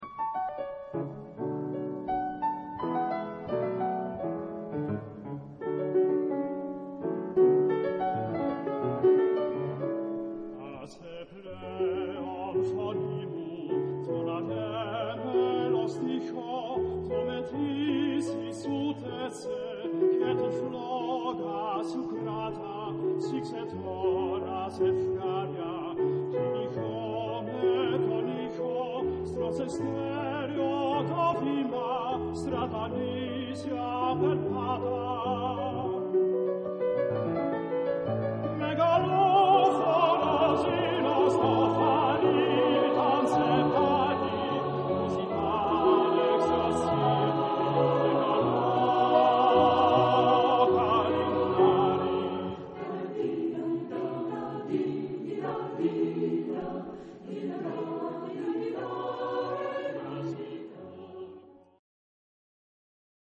Genre-Style-Forme : Chanson ; Folklore ; Profane
Caractère de la pièce : mélancolique ; passionné
Type de choeur : SATB  (4 voix mixtes )
Solistes : Baryton (1)  (1 soliste(s))
Instrumentation : Piano  (1 partie(s) instrumentale(s))
Tonalité : fa dièse mineur